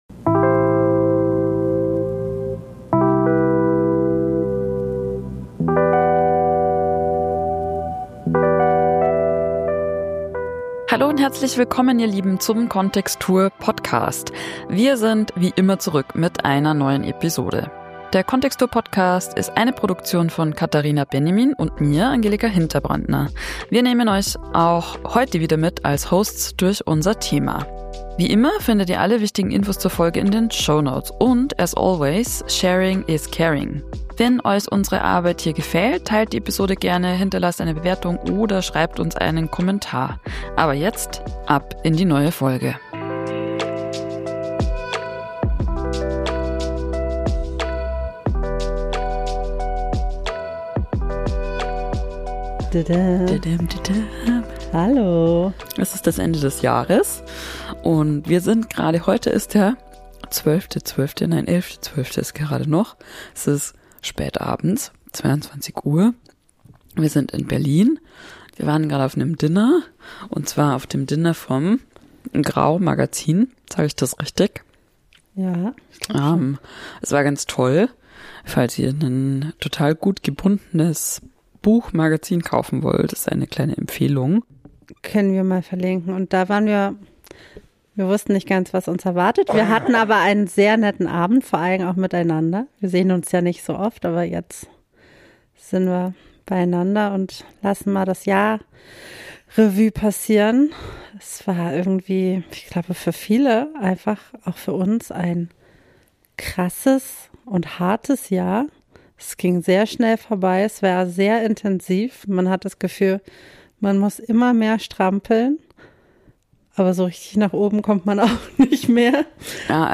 Zum Ende des Jahres besprechen wir die aktuelle Lage – schauen zurück und nach vorn. Ein bisschen müde, eine von uns erkältet und beide ein wenig angeheitert.